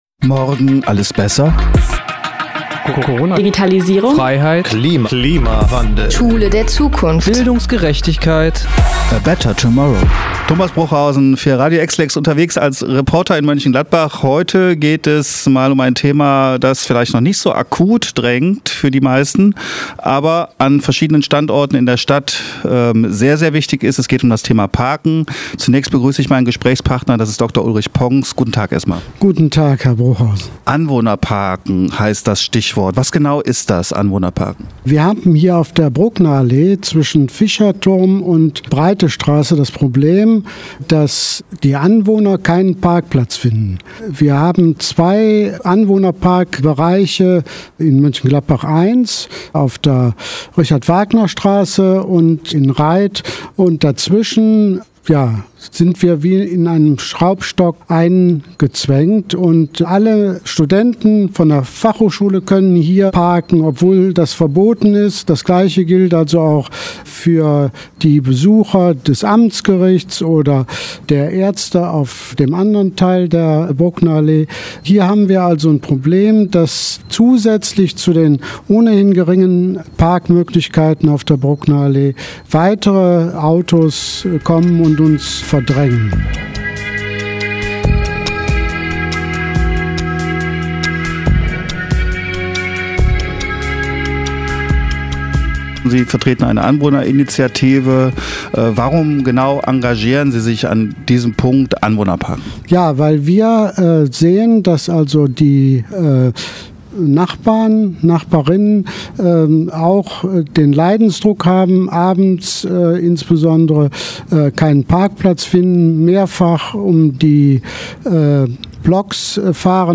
Im Interview mit EXLEX-Reporter